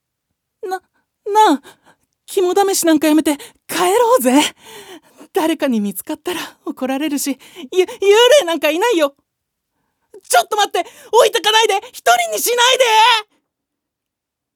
セリフ3